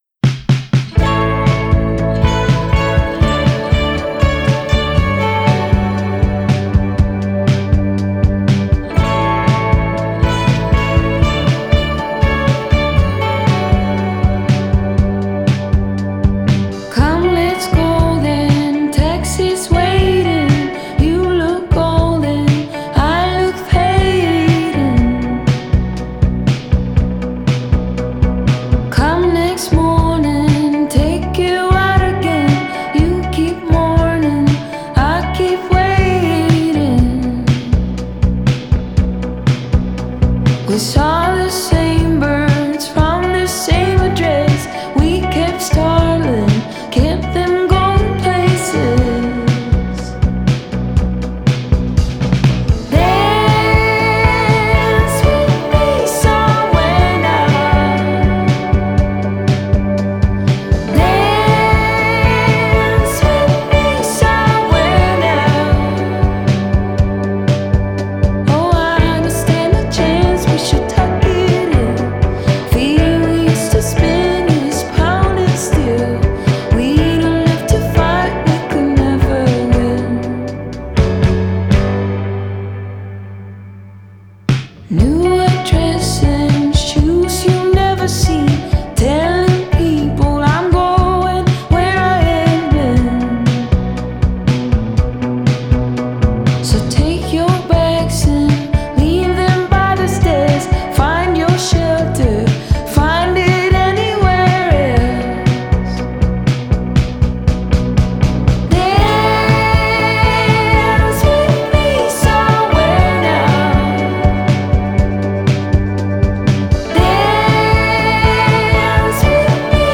Genre: Pop Folk